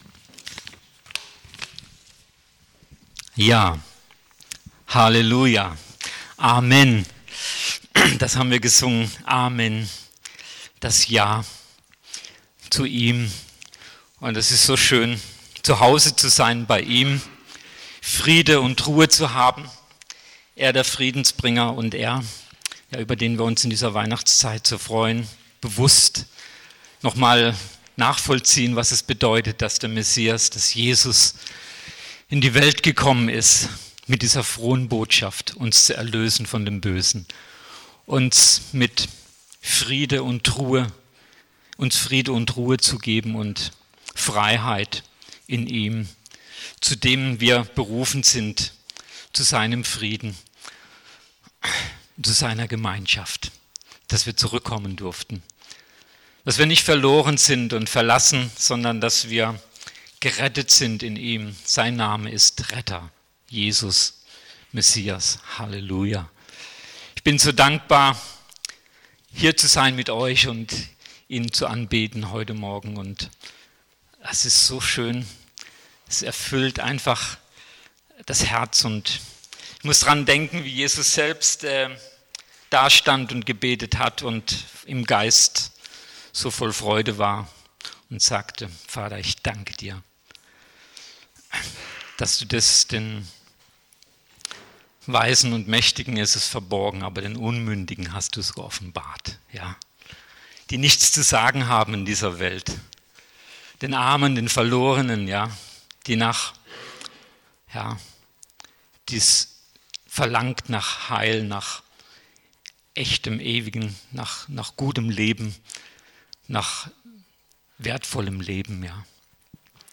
Predigt 30.12.2018: Komm…..